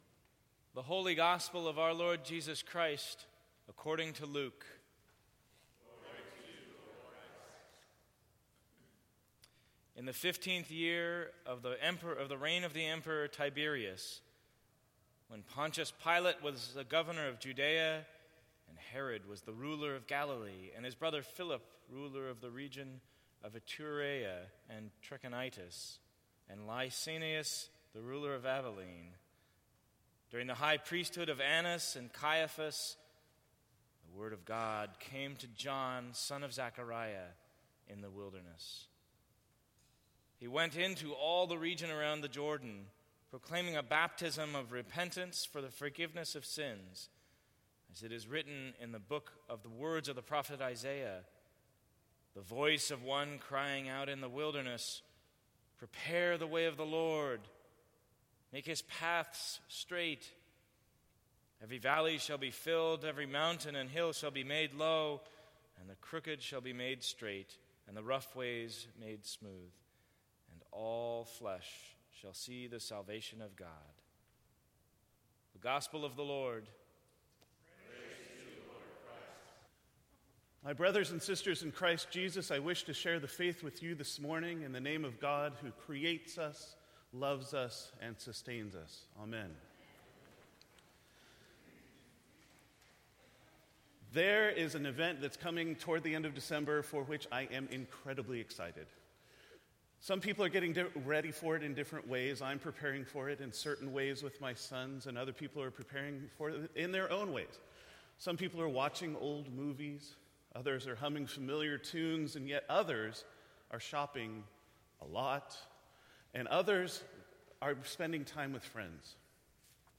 Sermons from St. Cross Episcopal Church I Will, With God’s Help Dec 14 2015 | 00:11:21 1x 00:00 / 00:11:21 Subscribe Share Apple Podcasts Spotify Overcast RSS Feed Share Link Embed